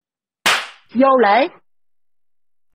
Thể loại: Câu nói Viral Việt Nam
Description: Vô Lễ meme sound effect là hiệu ứng âm thanh hài hước mô phỏng tiếng tát vào mặt kèm theo câu nói “Vô Lễ” đầy châm biếm và dí dỏm... tạo điểm nhấn gây cười, làm tăng tính kịch tính và phản ứng bất ngờ trong tình huống hài hước...
vo-le-meme-sound-effect-www_tiengdong_com.mp3.mp3